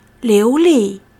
liu2--li4.mp3